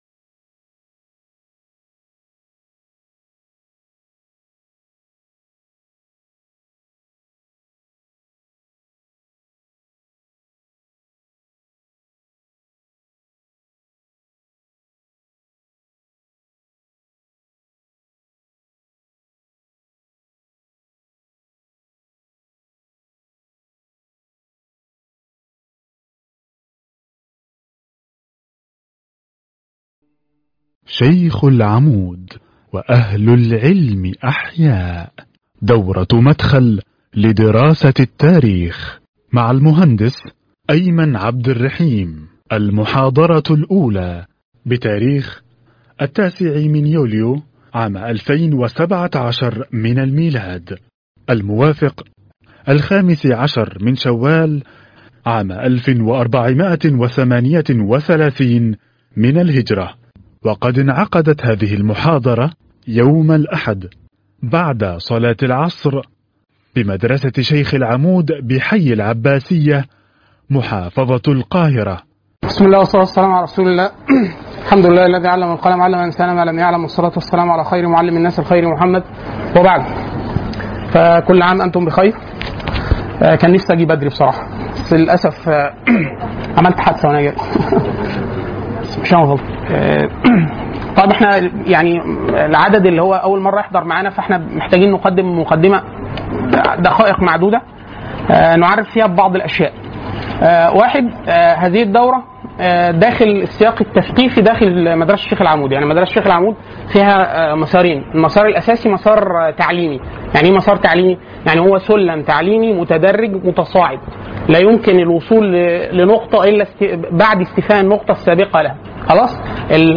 (1) مدخل لدراسة التاريخ الإسلامي (المحاضرة الأولى)